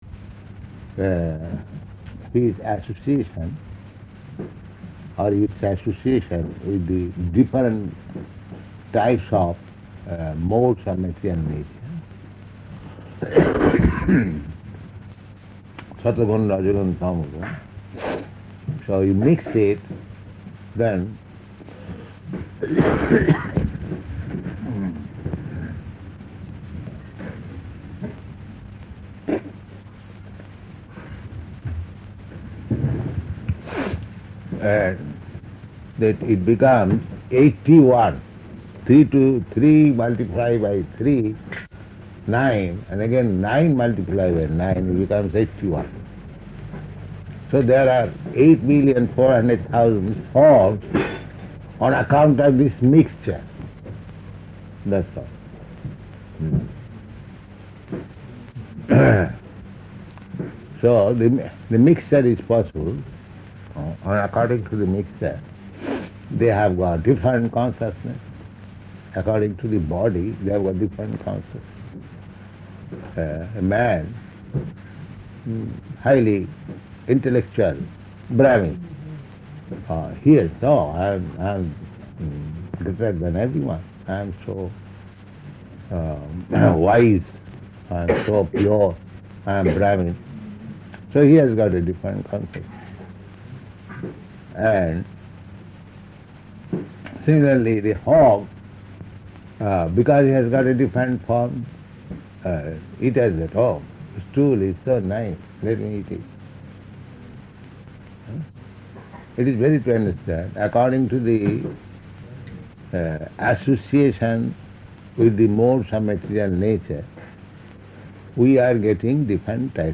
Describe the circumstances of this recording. Location: Tokyo